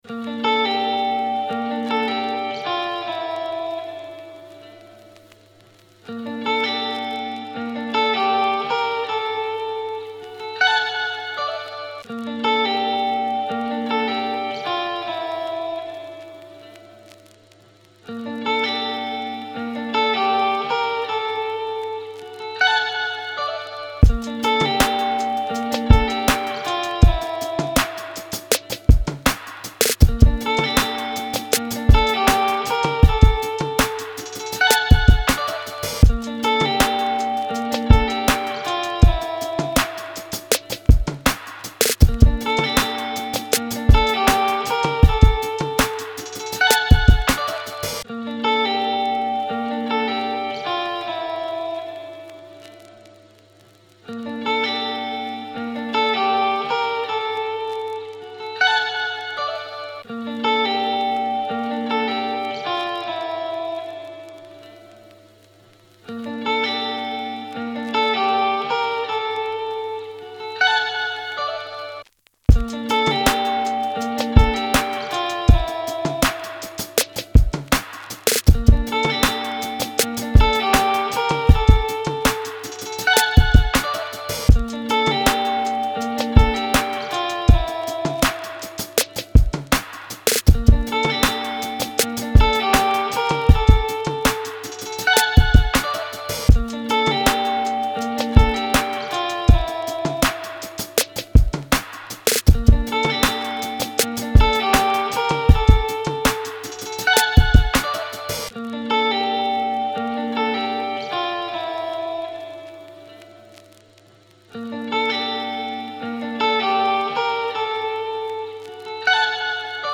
это инструментальная композиция в жанре эмбиент